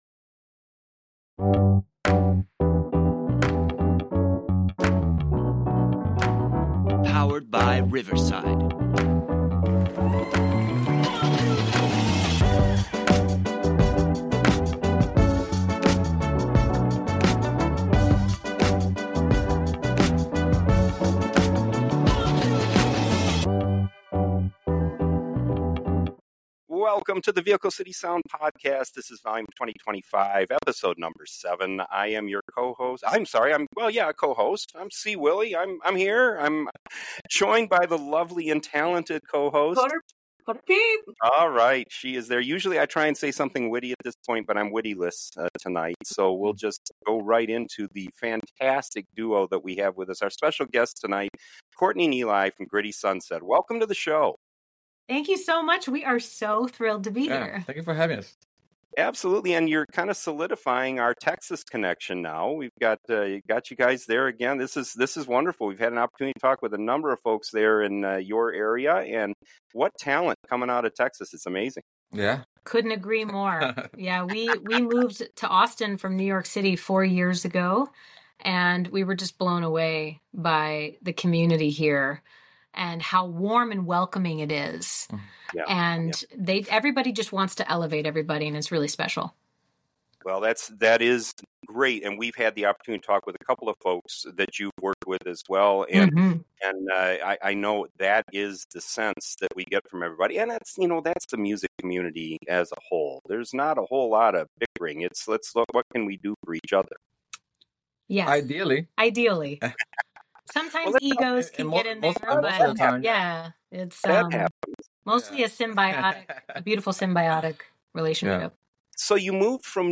pop and progessive jazz
truly amazing vocals